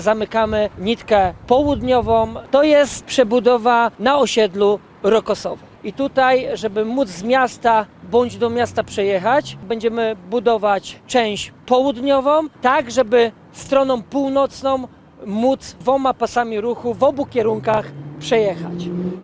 Więcej na temat utrudnień w ruchu – wiceprezydent miasta Tomasz Bernacki,